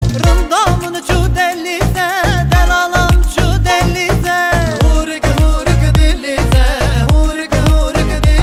Play bağlama, Download and Share now on SoundBoardGuy!